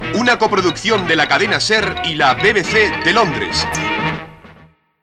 Careta de sortida